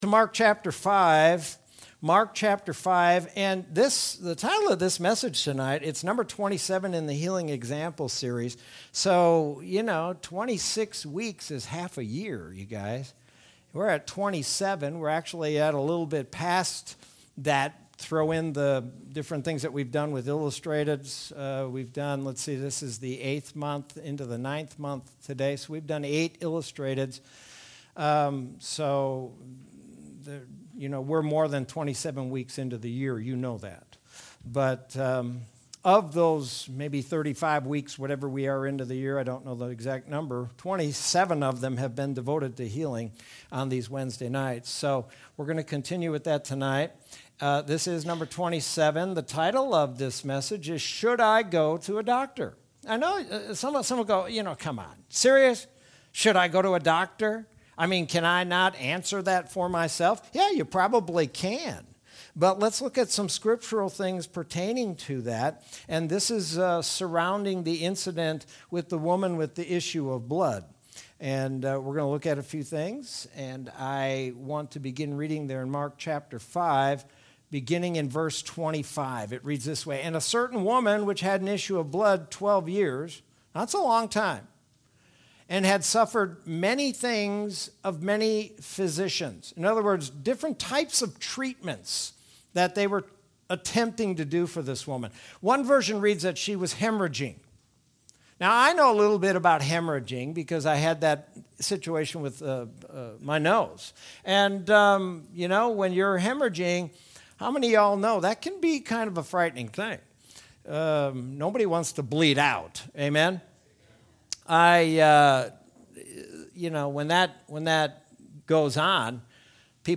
Sermon from Wednesday, September 1st, 2021.